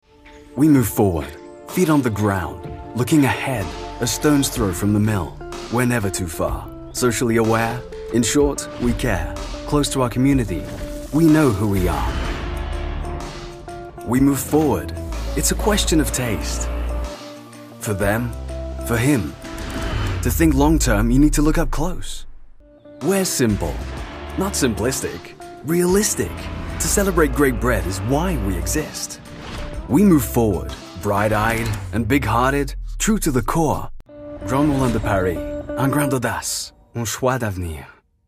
MALE VOICE OVER DEMOS AND EXTRACTS
Corporate MOULIN DE PARIS